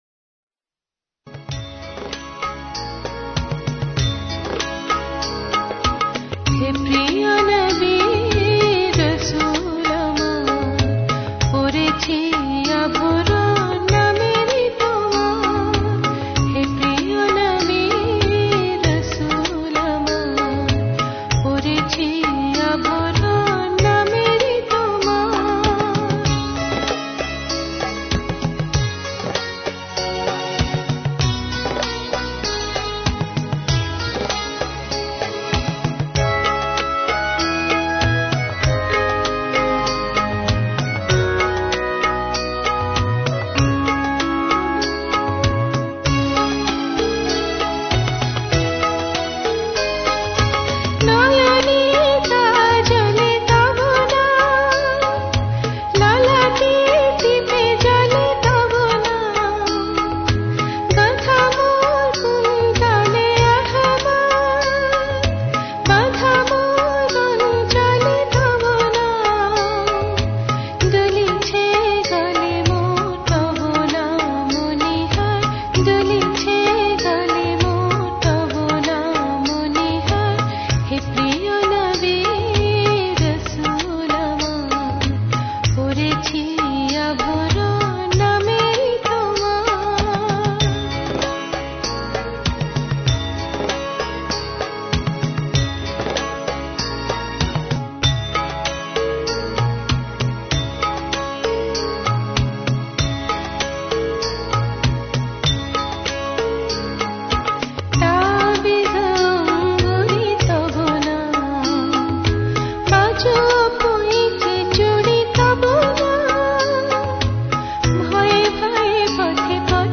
Hamd Naat